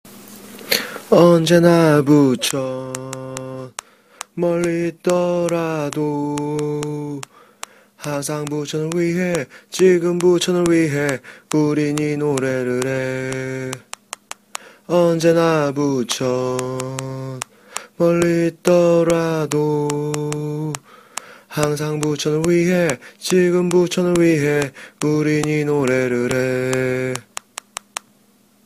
(야밤에 핸드폰 잡고 혼자 녹음 했으니 노약자 및 심장이 약하신 분들은 혼자 듣지 마시기 바랍니다)